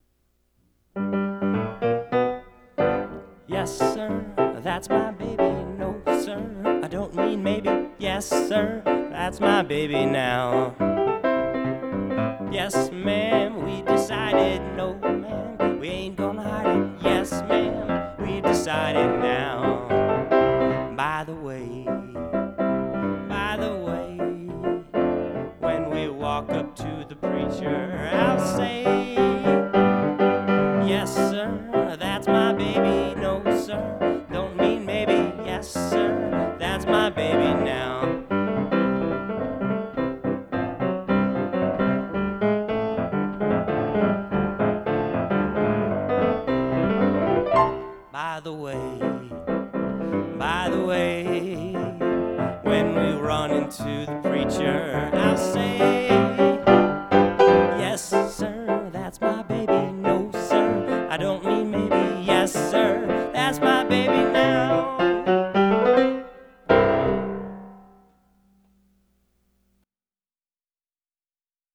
Yes-Sir-That_s-My-Baby-Steinway-Upright.wav